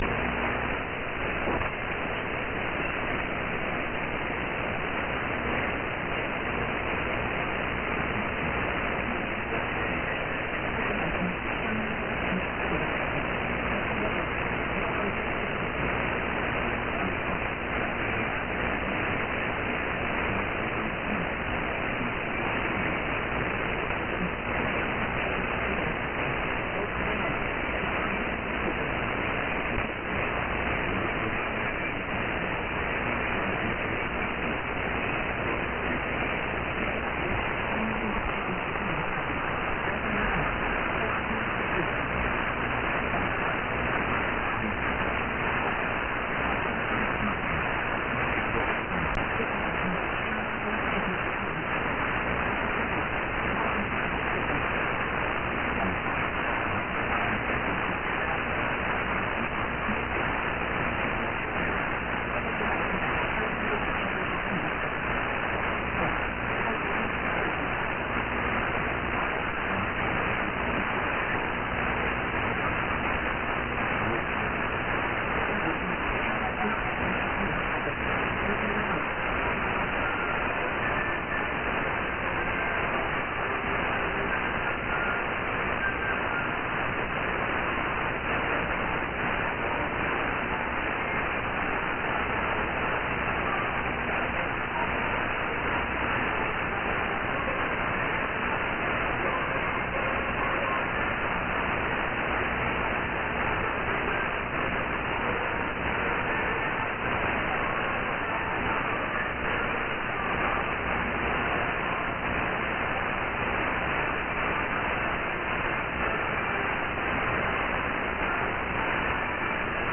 But the audio near the end sounded like what Sternradio plays.